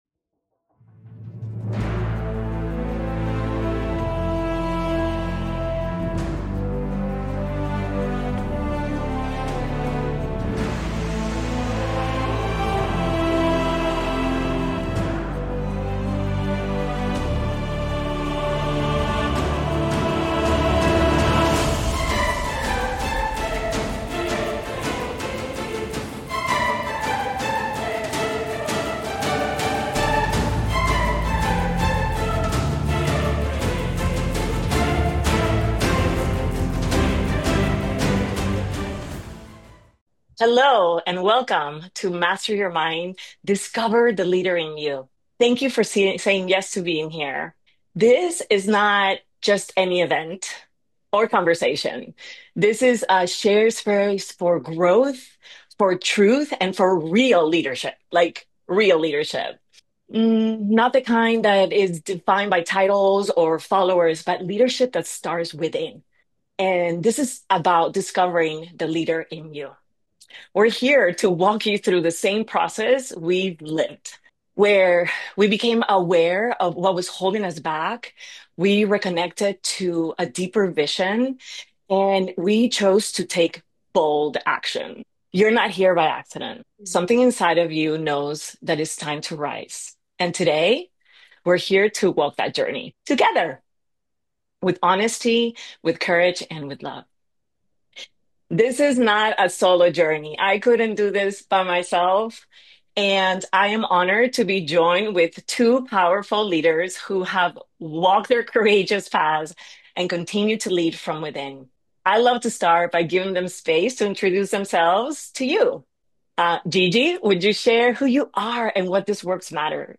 In a world full of distractions, pressure, and noise, this podcast on Master Your Mind: Discover the Leader in YOU offers a calm, clear voice that helps you tune in to your potential. This episode will help you explore three core pillars of becoming a confident, self-led individual: Awareness, Vision, and Action.